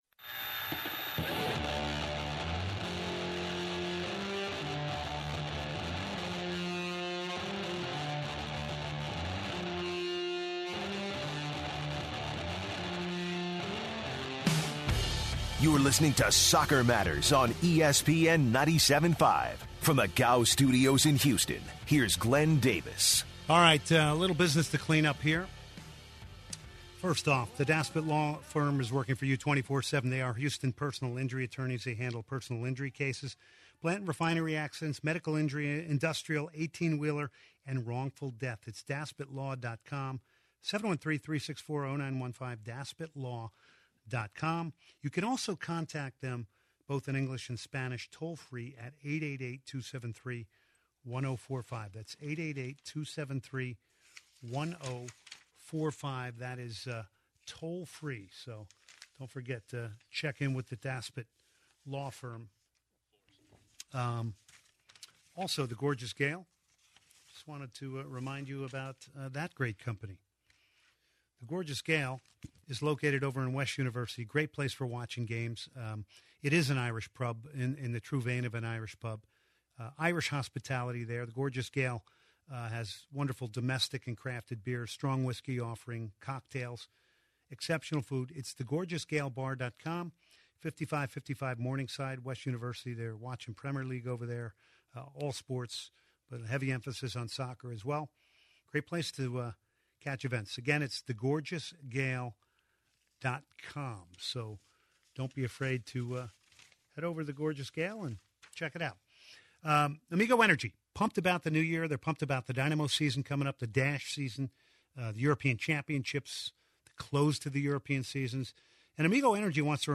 In hour two of Soccer Matters, we hear the second part of the Bob Bradley interview continued from last week. In the interview, Bob talks about some of his favorite places in Cairo and how he adjusted to living in Egypt and how he went about coaching and training the Pharaohs.